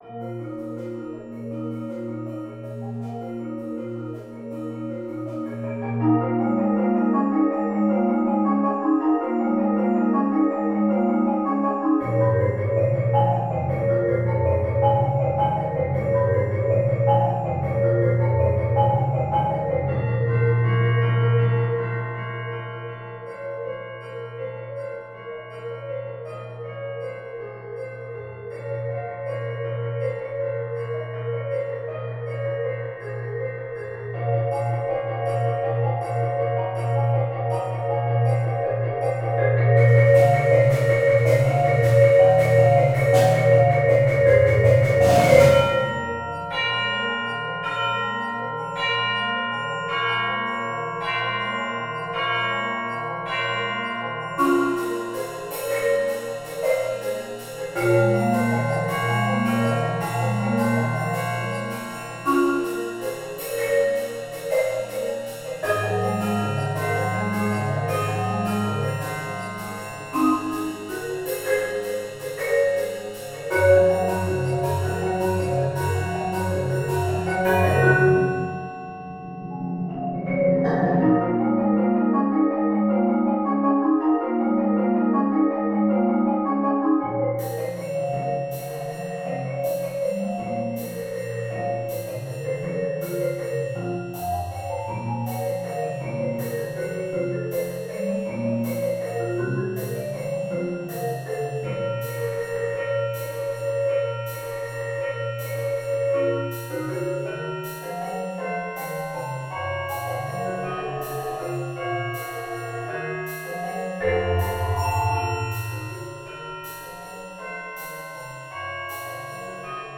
Genre: Percussion Ensemble
# of Players: 9
Percussion 1 (bells, triangle)
Percussion 2 (crotales, snare drum, bass drum)
Percussion 3 (chimes)
Percussion 4 (4-octave marimba, vibraphone)
Percussion 7 (4.5-octave marimba)
Percussion 8 (4-5 timpani)
Percussion 9 (ride cymbal, gong)